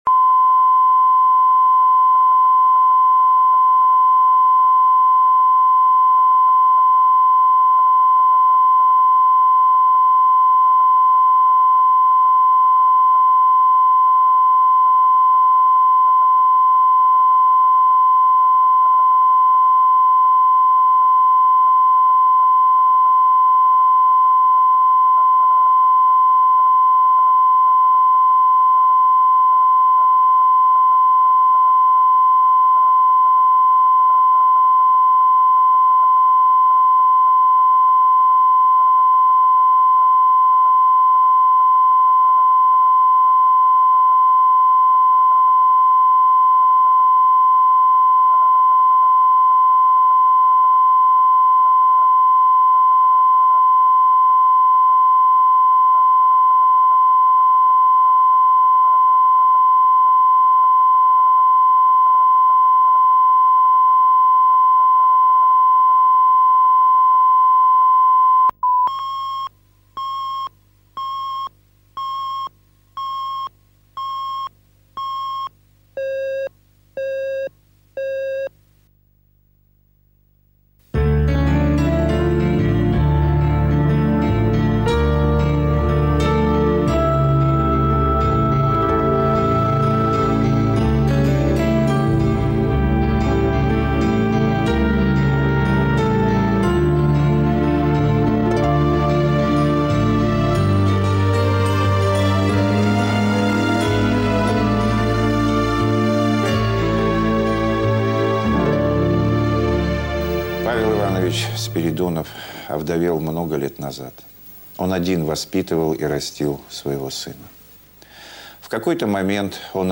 Аудиокнига Взрослый сын | Библиотека аудиокниг
Прослушать и бесплатно скачать фрагмент аудиокниги